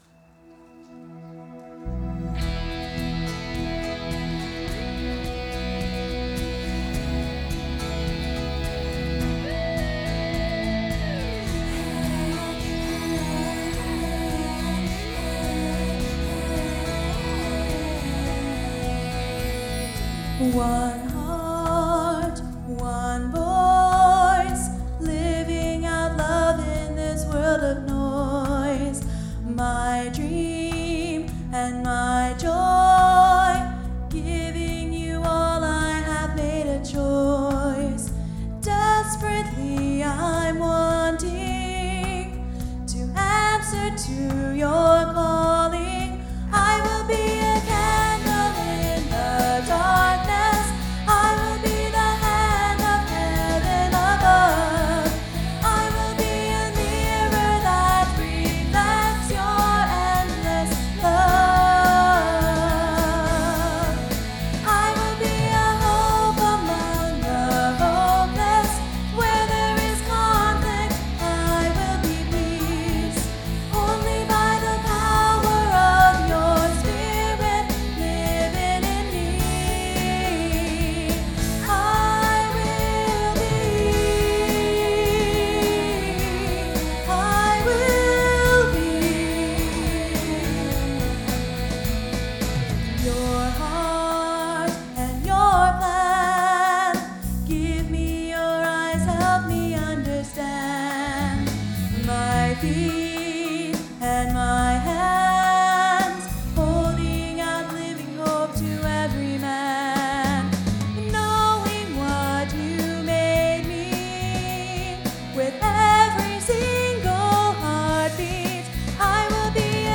Sunday Morning Music
Solo